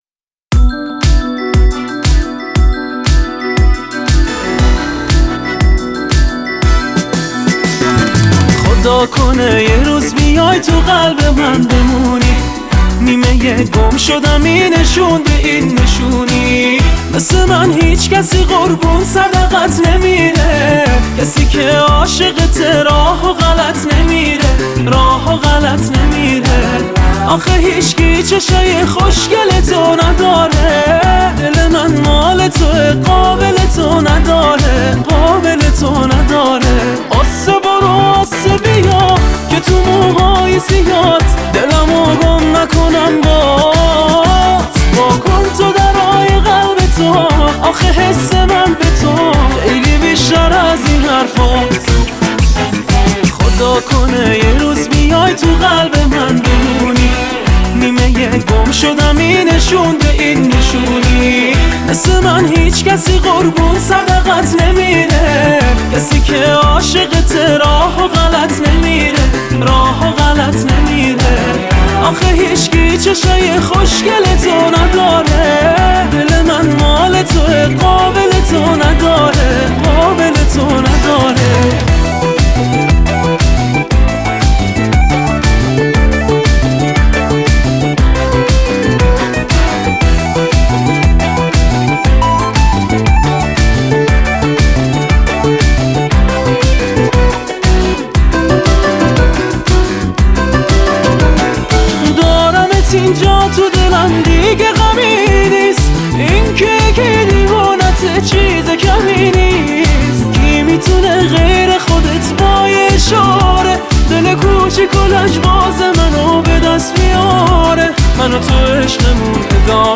پاپ
اهنگ ایرانی